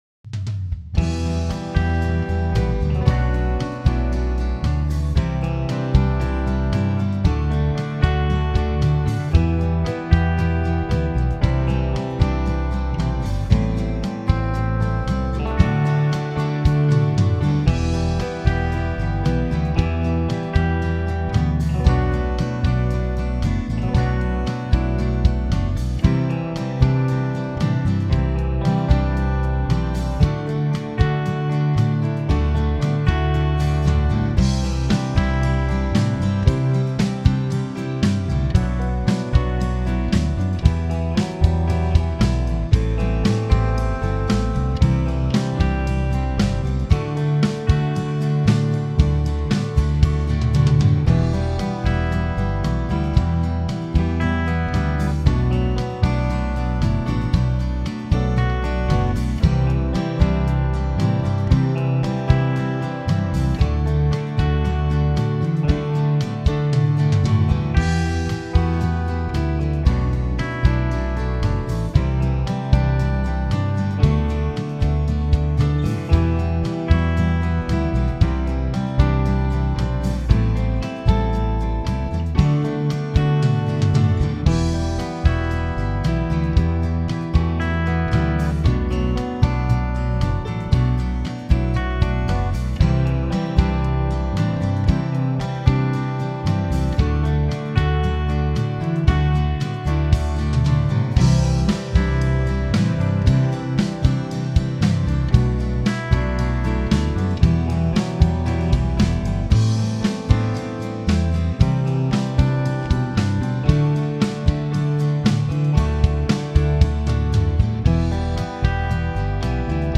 Home > Music > Blues > Bright > Smooth > Medium